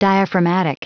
Prononciation du mot diaphragmatic en anglais (fichier audio)
Prononciation du mot : diaphragmatic
diaphragmatic.wav